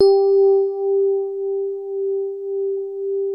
E-PIANO 1
TINE SOFT G3.wav